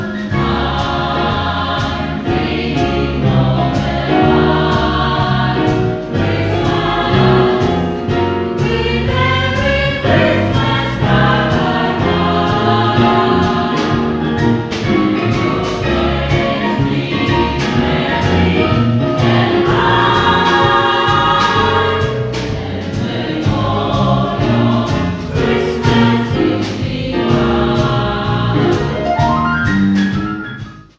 Koncert z cyklu „Wielobarwne Suwałki” odbył się w niedzielę (22.12.19), w Suwałkach.
Wszystko w miejscowym kościele ewangelickim.
Muzycy zaśpiewali kolędy oraz inne znane i lubiane piosenki świąteczne.
kolędy_strona.mp3